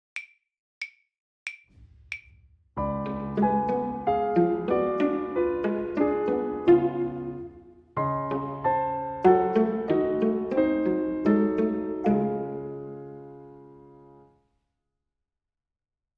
Claviers
91 - Page 42 - déchiffrage 1 - piano seul